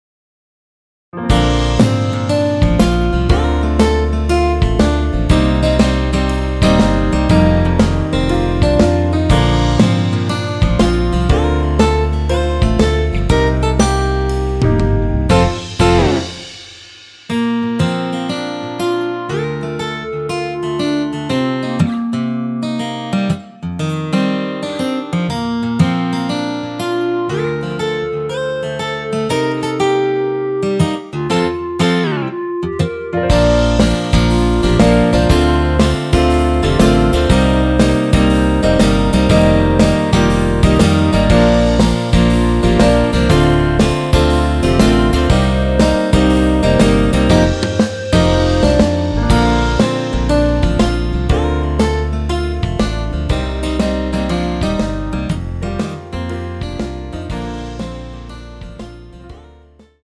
원키에서(-1)내린 멜로디 포함된 MR입니다.
Bb
멜로디 MR이라고 합니다.
앞부분30초, 뒷부분30초씩 편집해서 올려 드리고 있습니다.
중간에 음이 끈어지고 다시 나오는 이유는